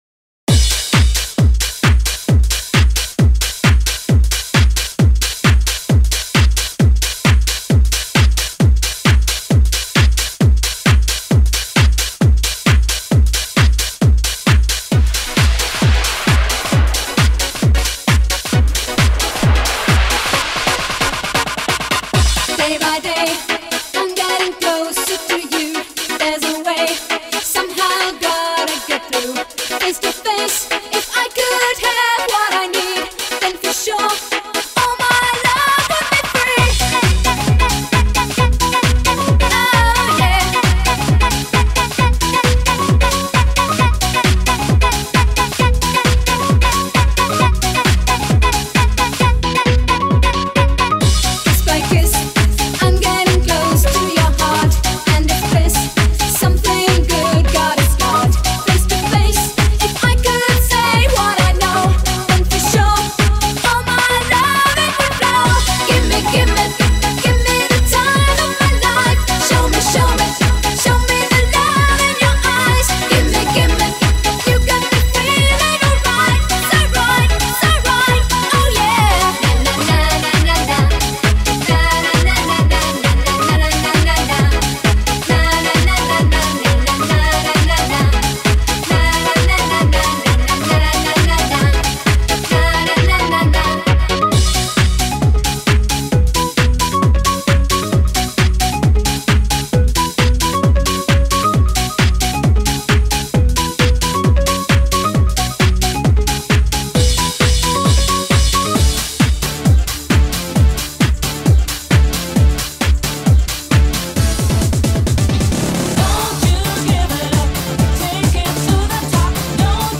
Eurodance_Megamix___Back_to_the_90_part_2_.mp3